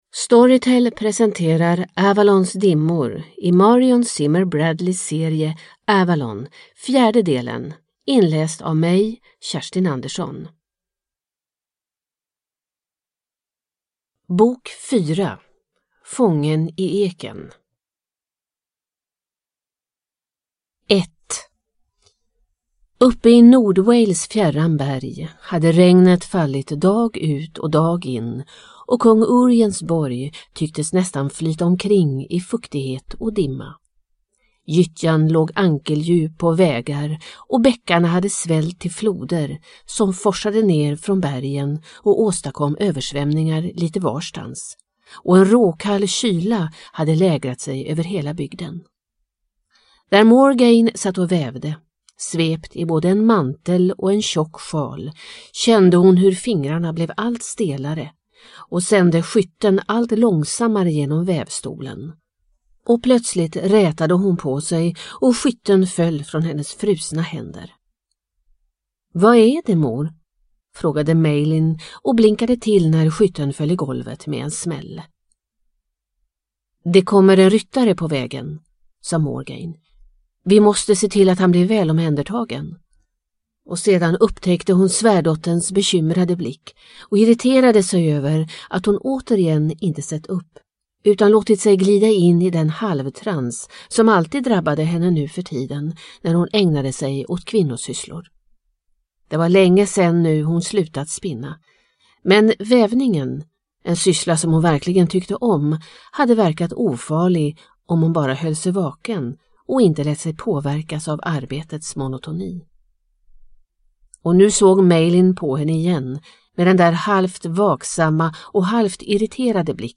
Avalons dimmor – del 4 – Ljudbok – Laddas ner